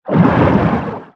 Sfx_creature_pinnacarid_swim_slow_02.ogg